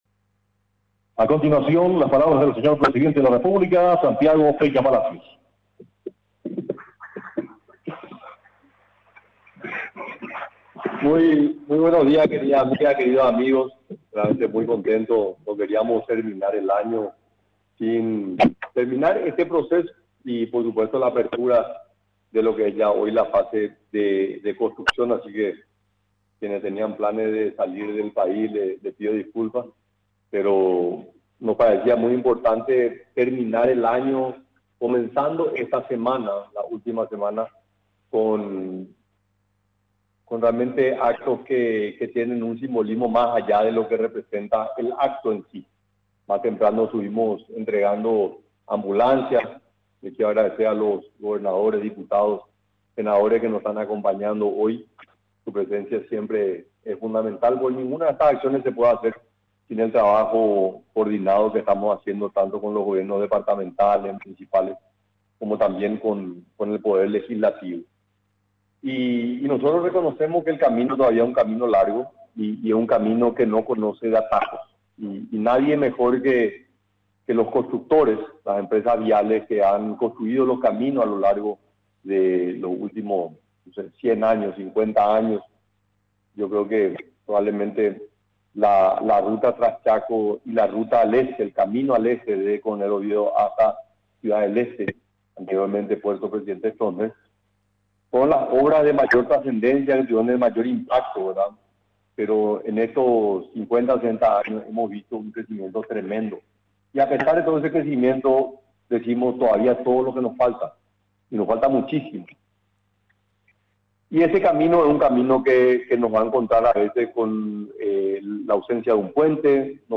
Refirió, durante el acto realizado en el Salón Tirika de la Residencia Presidencial de Mburuvichá Róga, que este proyecto fue adjudicado, a través de la Resolución N° 1934 del MOPC, correspondiente a la Licitación Pública Nacional para el Diseño y Construcción de la Pavimentación Asfáltica de la Ruta Nacional PY10, tramo San Cristóbal–Paso Yobái (ID N° 456.747).